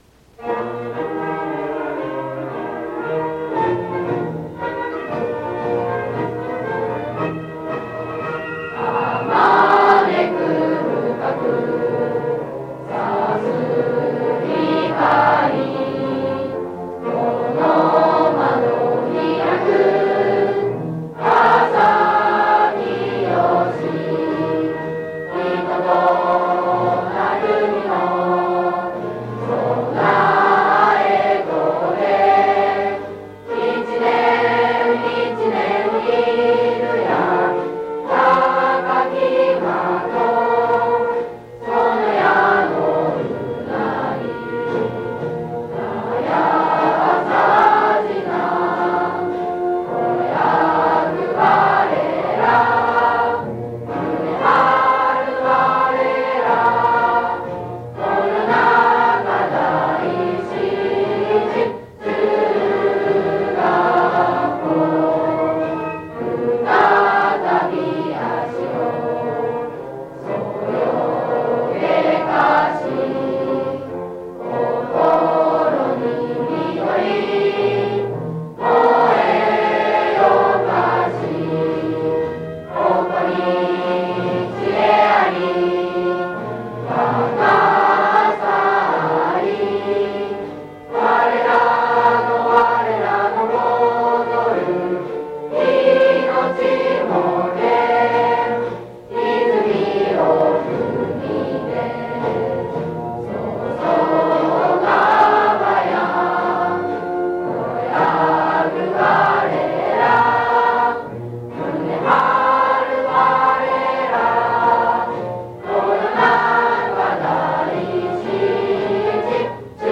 校歌 - 第七中学校